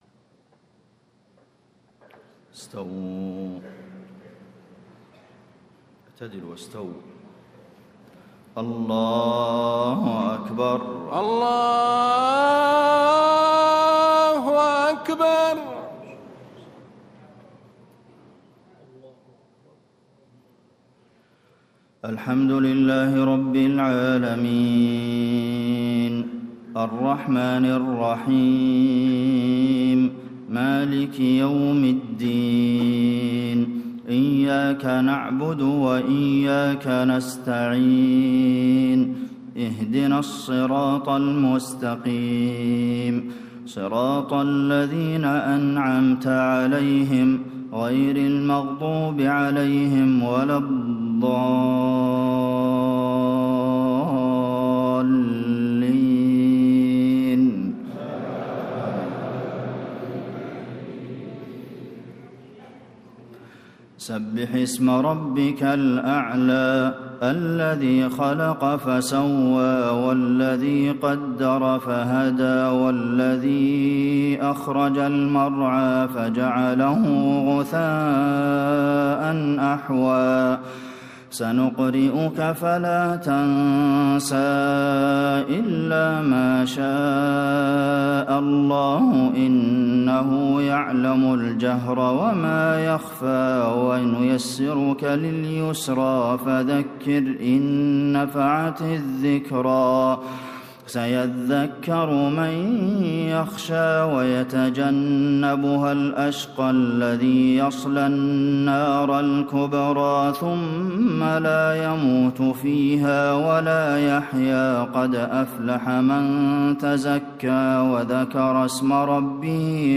صلاة الجمعة 6 شعبان 1437هـ سورتي الأعلى و الغاشية > 1437 🕌 > الفروض - تلاوات الحرمين